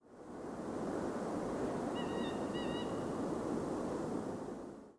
1 – wailing calls
Notes can be prolonged and have a slightly wailing or moaning quality, as in the second example below.
Coot typical call variant